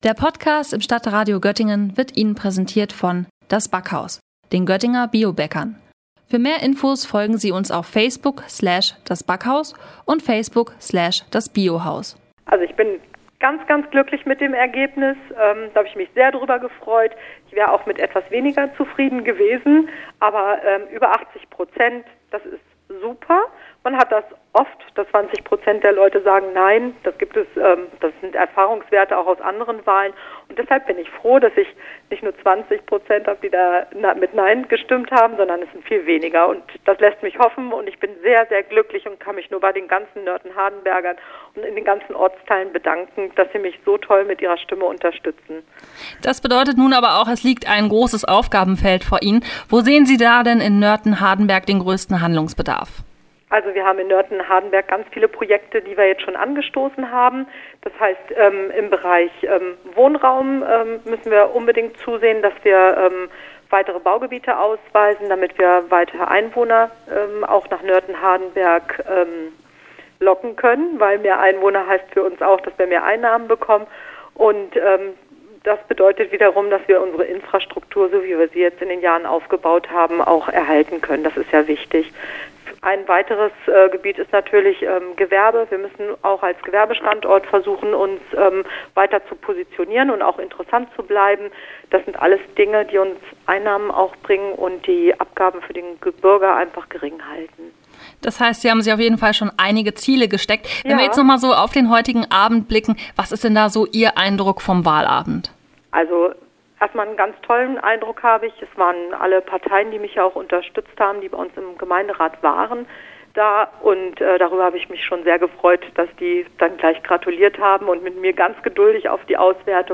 Beiträge > Interview mit Susanne Glombitza, neue Bürgermeisterin in Nörten-Hardenberg - StadtRadio Göttingen